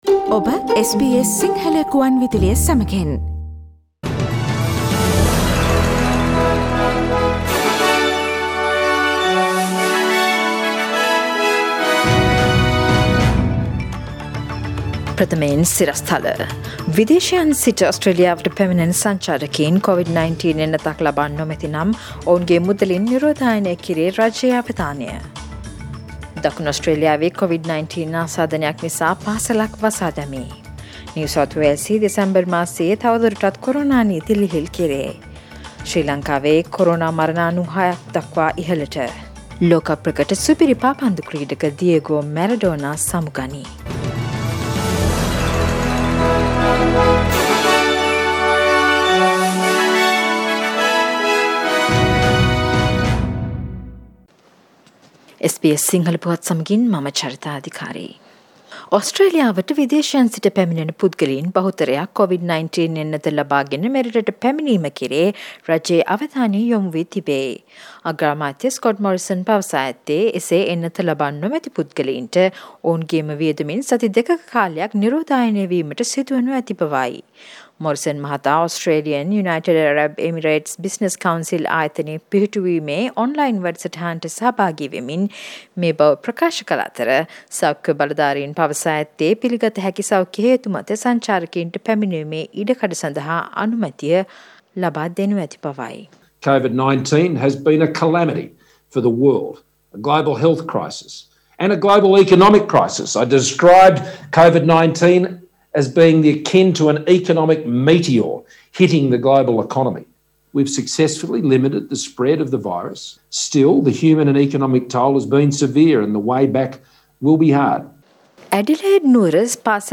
Daily News bulletin of SBS Sinhala Service: Thursday 26 November 2020
Today’s news bulletin of SBS Sinhala radio – Thursday 26 November 2020.